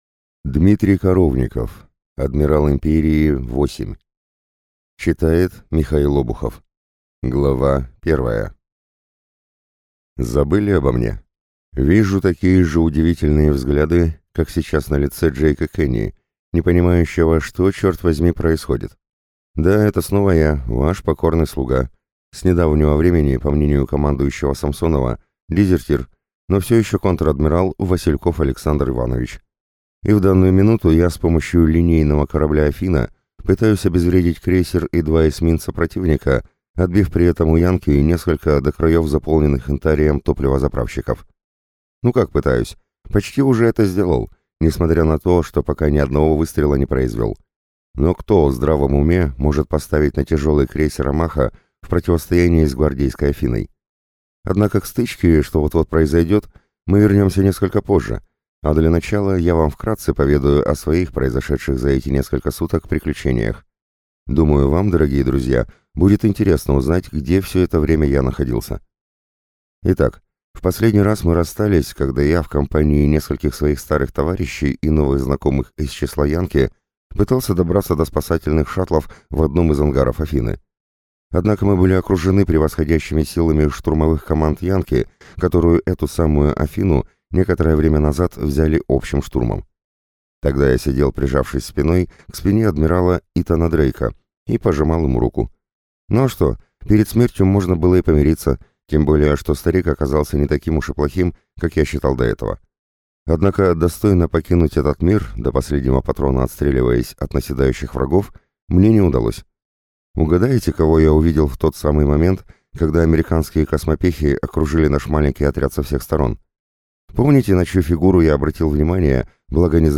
Аудиокнига Адмирал Империи – 8 | Библиотека аудиокниг